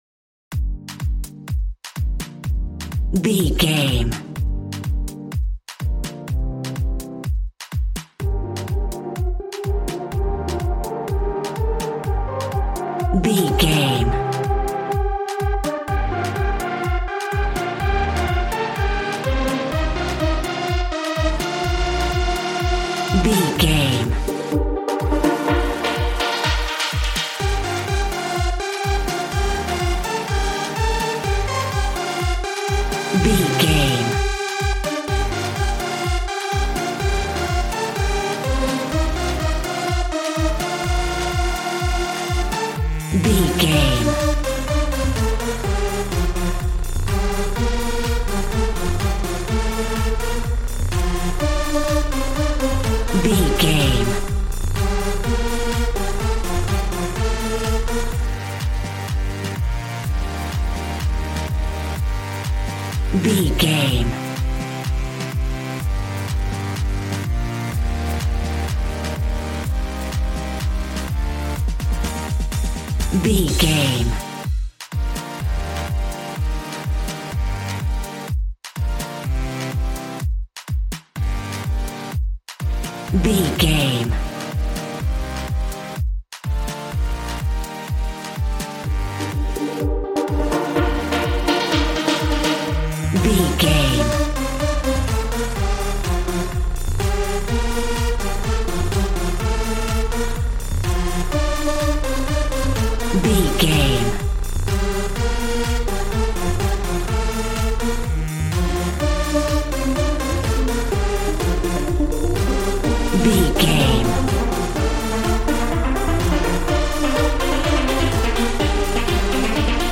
Aeolian/Minor
Fast
uplifting
lively
groovy
synthesiser
drums